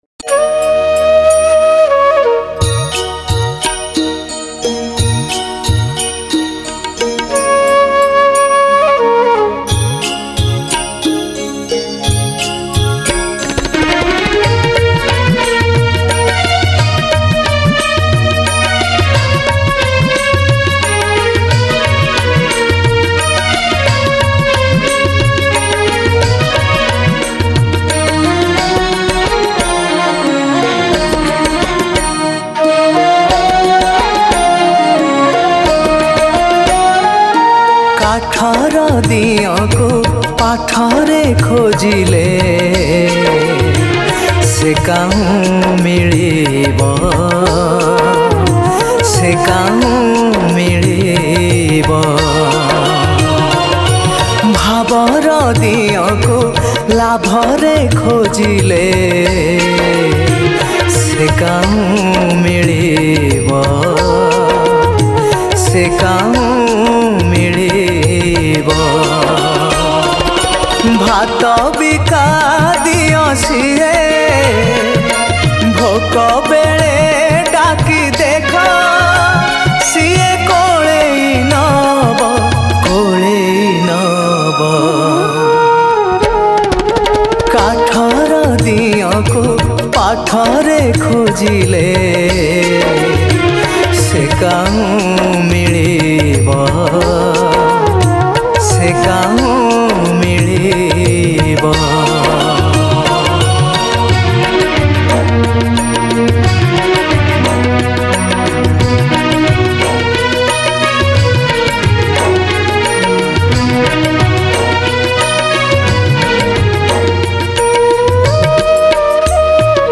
Odia New Bhajan